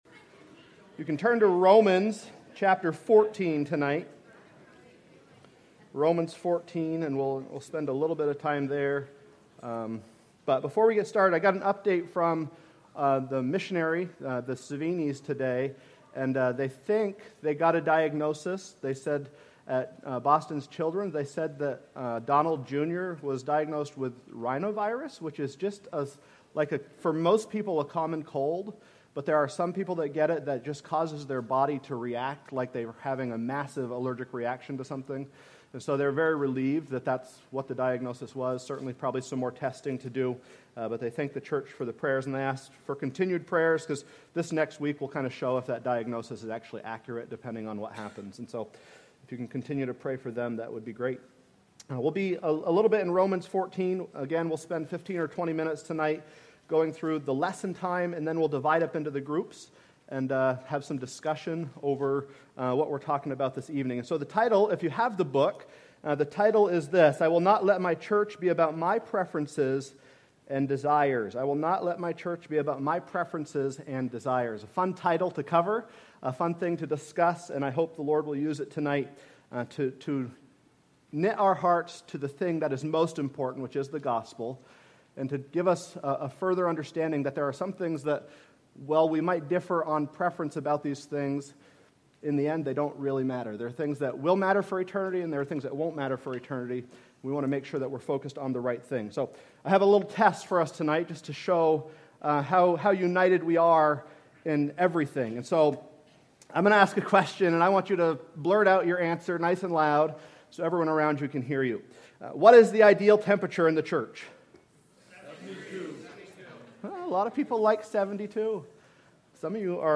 Sermons by Northside Baptist Church